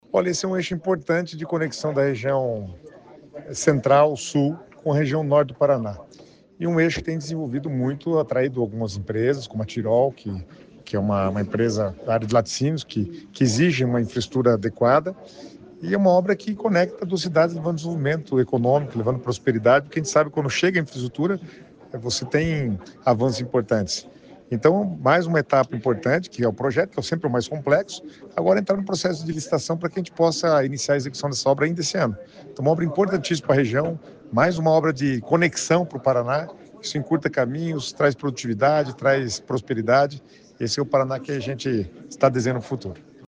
Sonora do secretário das Cidades, Guto Silva, sobre a pavimentação da PR-487 entre Ivaí e Ipiranga